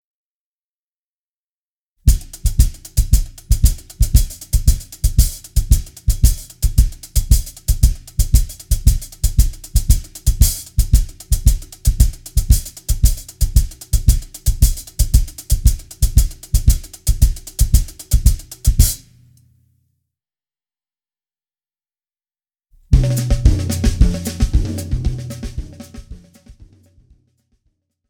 Besetzung: Schlagzeug
13 - Samba-Groove 1